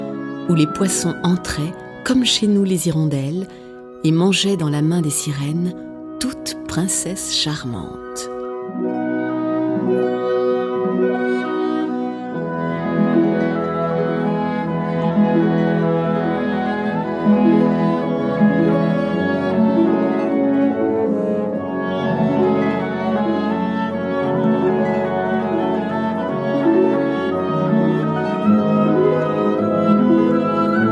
Livre lu